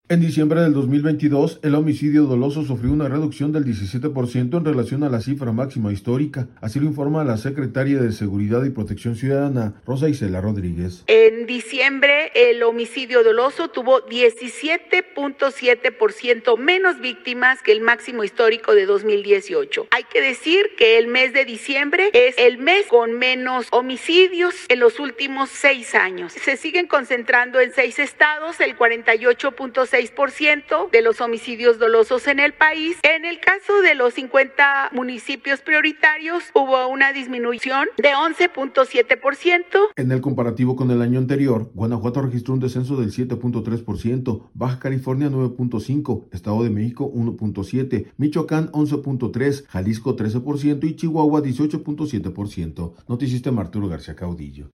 En diciembre del 2022, el homicidio doloso sufrió una reducción del 17 por ciento en relación a la cifra máxima histórica, así lo informa la secretaria de Seguridad y Protección Ciudadana, Rosa Icela Rodríguez.